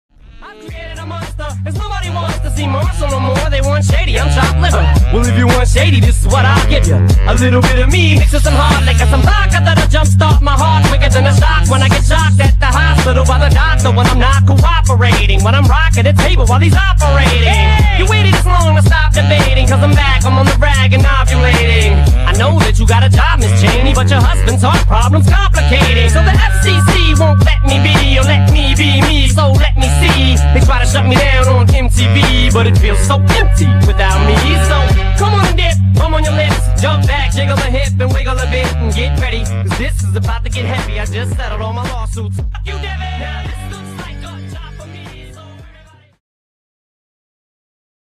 Hip-Hop
16_hiphop__fragment.mp3